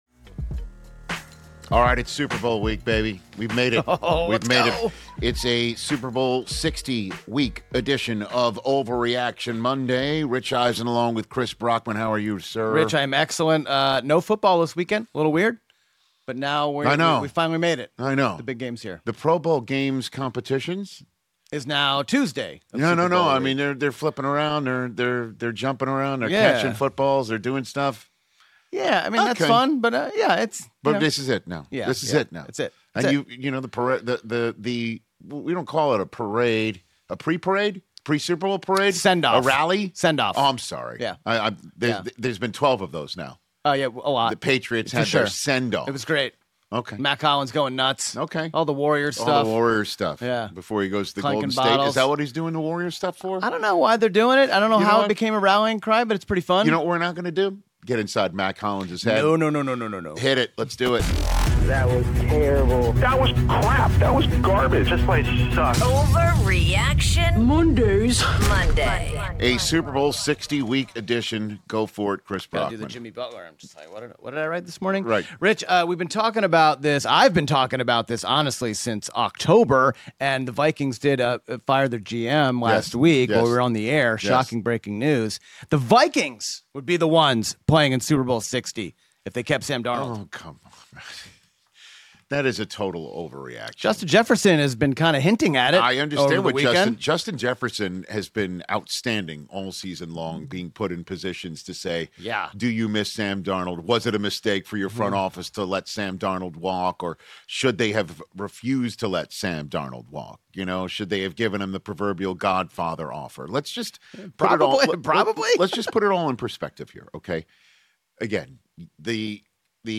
debating the latest in the NFL